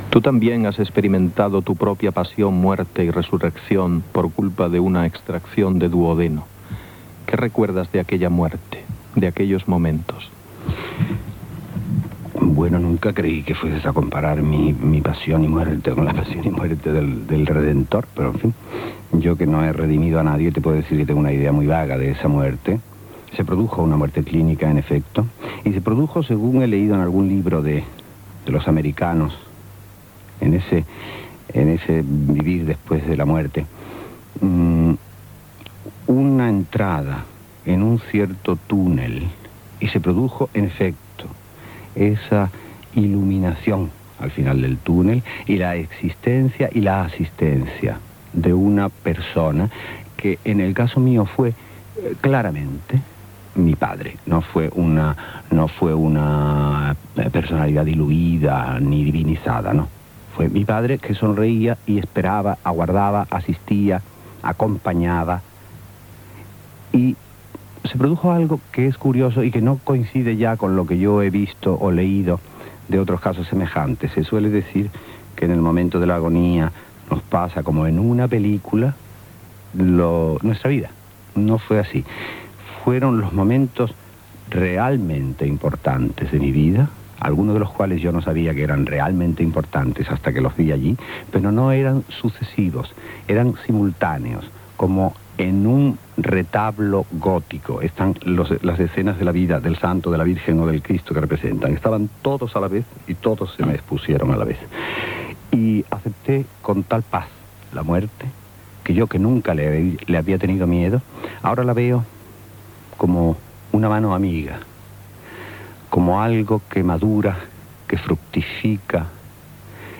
Entrevista a l'escriptor Antonio Gala sobre la seva vida personal